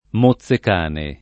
[ mo ZZ ek # ne ]